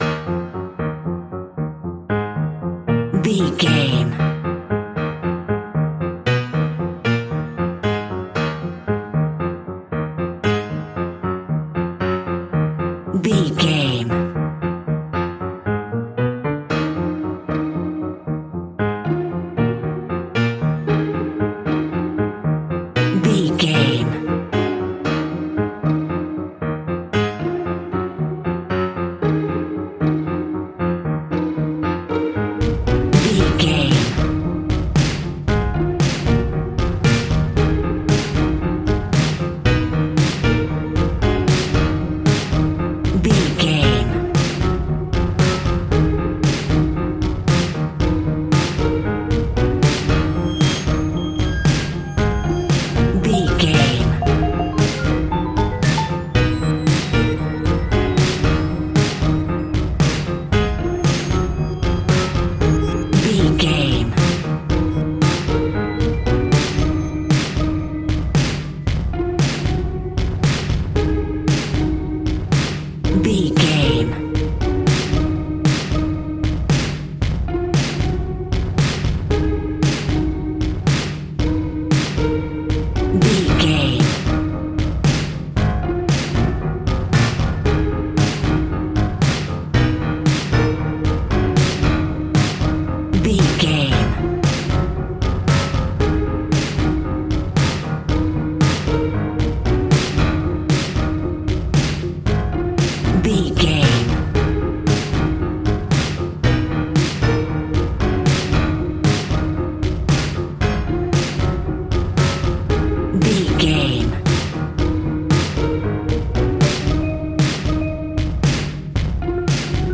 Aeolian/Minor
E♭
tension
ominous
dark
suspense
dramatic
eerie
piano
strings
drums
cymbals
gongs
viola
french horn trumpet
taiko drums
timpani